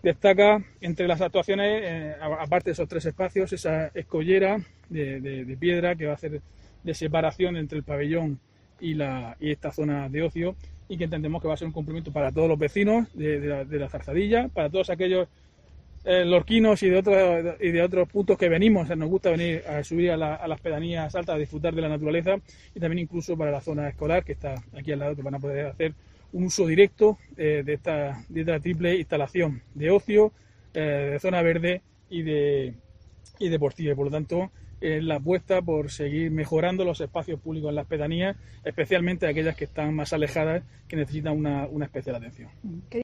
Diego José Mateos, alcalde de Lorca sobre visita a Zarzadilla de Totana